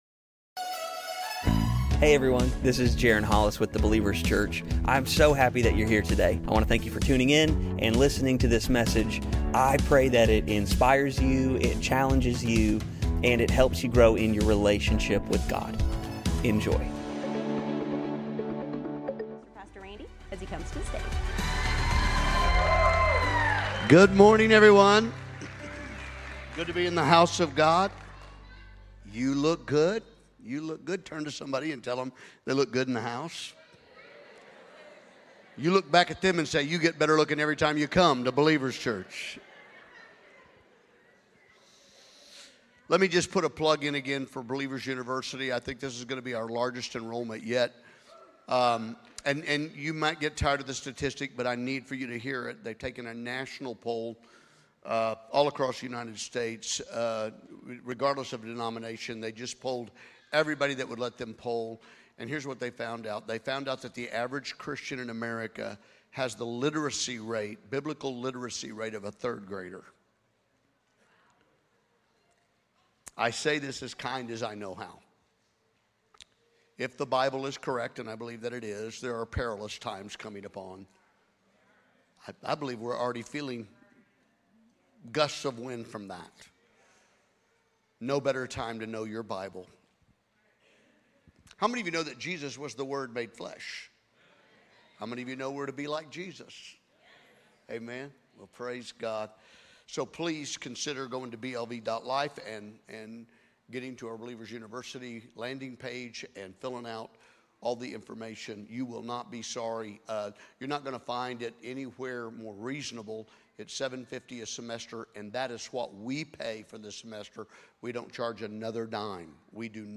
The Believers Church - Sunday Messages